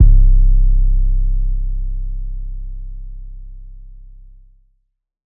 {808} COORDINATE.wav